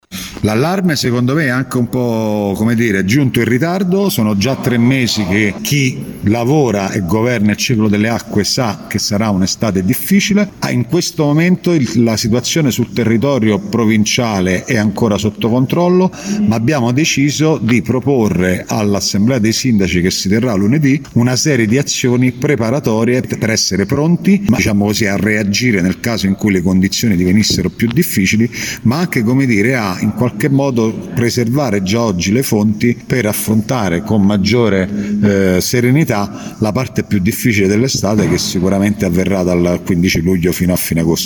Lo ha detto il presidente dell’Ente, Gerardo Stefanelli oggi a margine di una conferenza stampa durante la quale si è parlato della questione rifiuti.